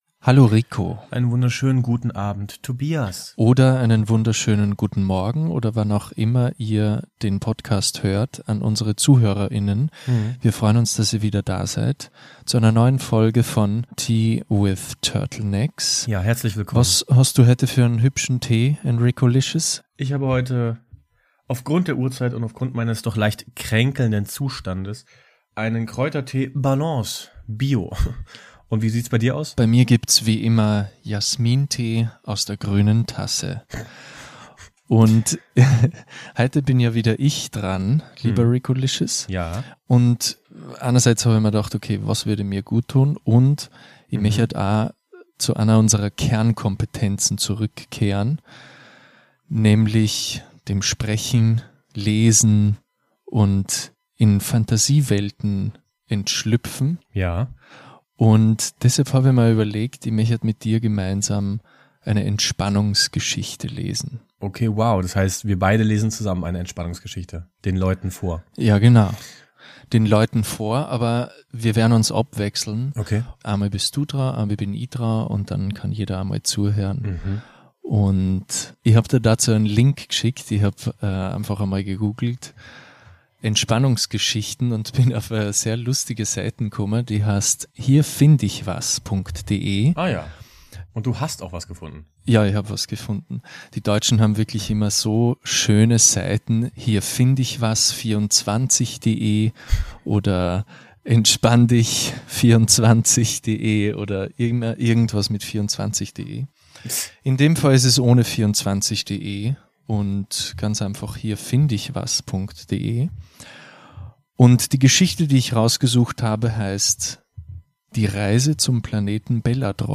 Wir lassen dir hier mal zur Abwechslung ein auditives Entspannungsbad ein. Komm mit auf eine Reise, oszillierend zwischen "hörspiel-retreat" und "eso-cringe". Heute ist uns mal alles egal und wir erforschen diesen eigenartigen Planeten namens "Belladron".